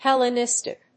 音節Hel・le・nis・tic 発音記号・読み方
/hèlənístɪk(米国英語), ˈhɛl.ən.ɪs.tɪk(英国英語)/